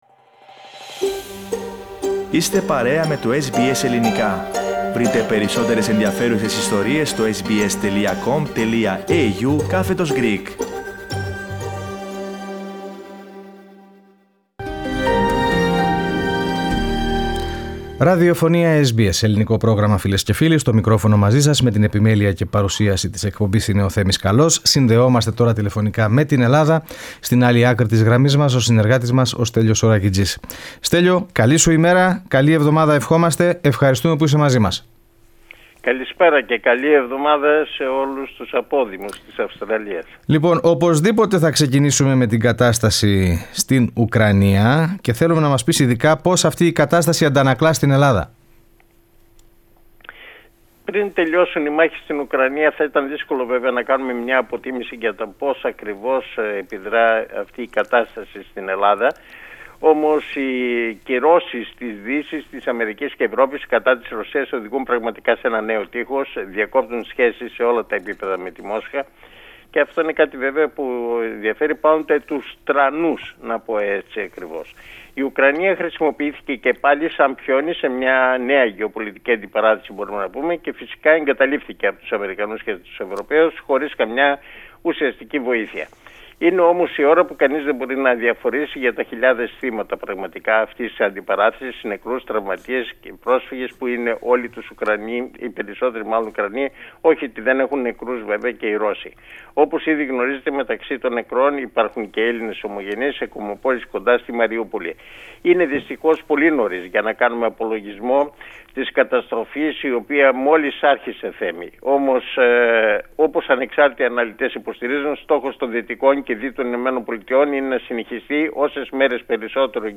Στην σημερινή ανταπόκριση από Ελλάδα, η στάση της χώρας υπέρ των Ουκρανών, η πολιτική των Τούρκων σχετικά με τον πόλεμο και ο στόχος των ΗΠΑ να συνεχιστεί η τραγωδία όσο το δυνατόν περισσότερο.